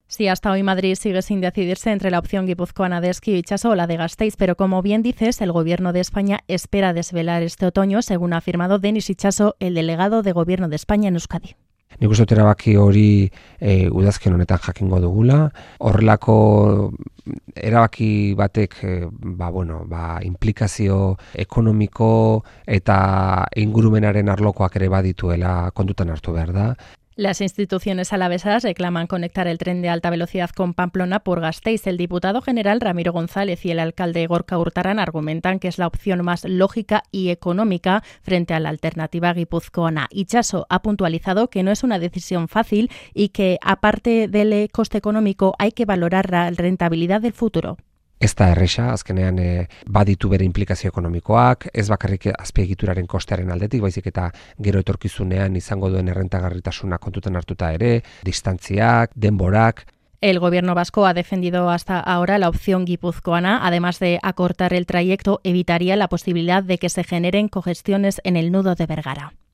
Así lo ha expresado en Euskadi Irratia el delegado del Gobierno de España en la Comunidad Autónoma Vasca, Denis Itxaso.